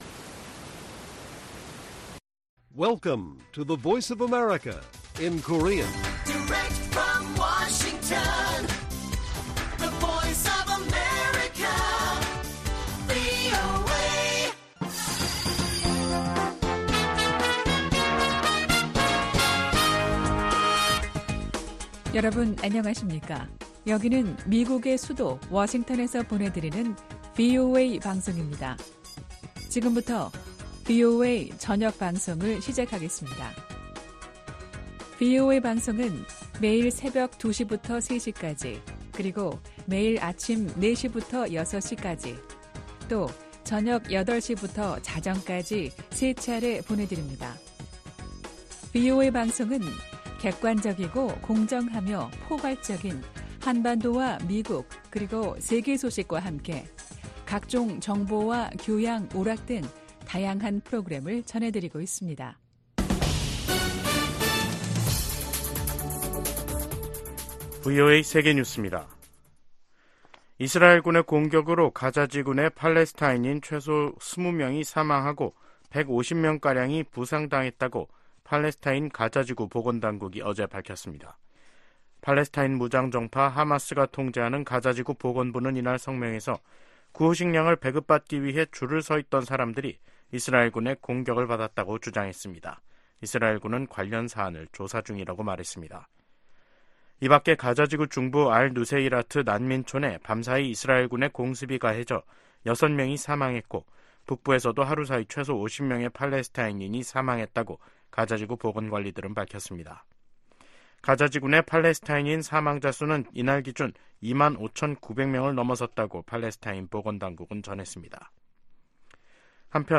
VOA 한국어 간판 뉴스 프로그램 '뉴스 투데이', 2024년 1월 26일 1부 방송입니다. 제네바 군축회의에서 미국과 한국 등이 북한의 대러시아 무기 지원을 규탄했습니다. 미 국방부는 북한의 대러시아 무기 지원이 우크라이나 침략 전쟁을 장기화한다고 비판했습니다. 김정은 북한 국무위원장은 지방 민생이 생필품 조차 구하기 어려운 수준이라면서, 심각한 정치적 문제라고 간부들을 질타했습니다.